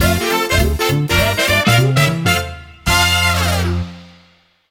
Game rip
Fair use music sample